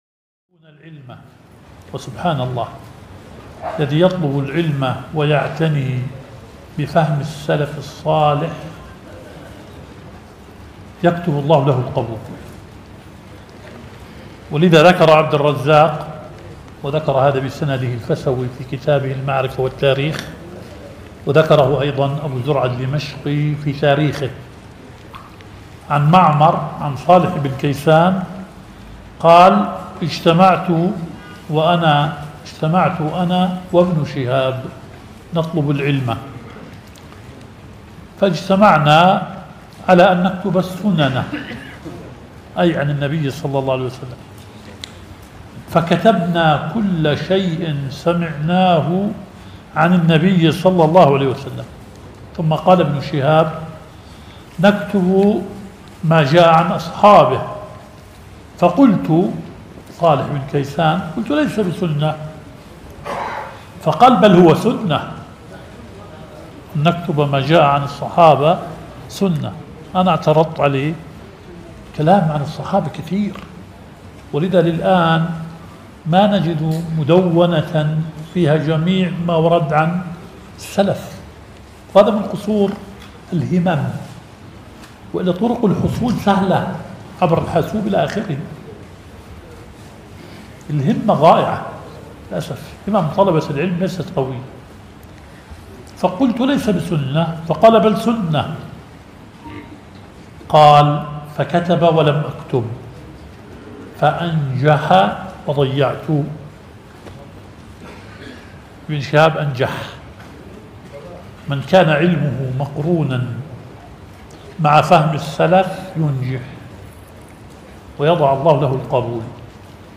البث المباشر لدرس شيخنا شرح صحيح مسلم